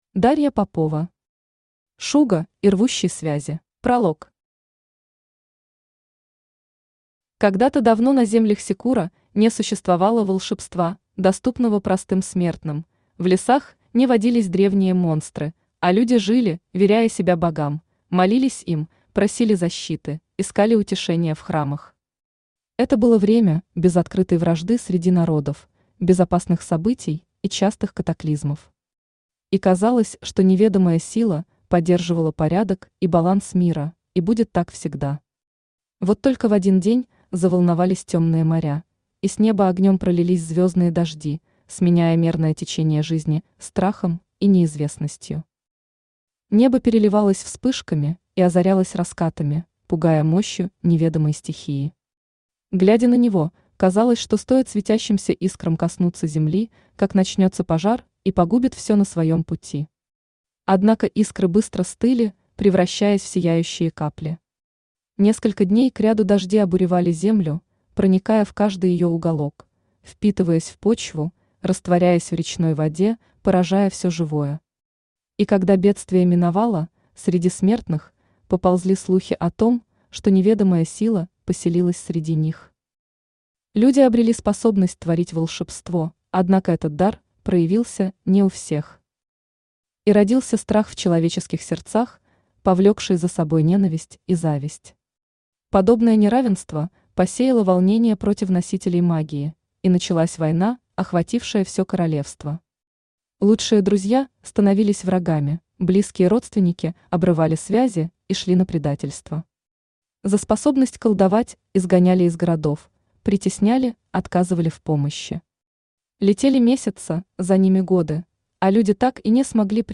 Аудиокнига Шуго и Рвущий связи | Библиотека аудиокниг
Aудиокнига Шуго и Рвущий связи Автор Дарья Андреевна Попова Читает аудиокнигу Авточтец ЛитРес.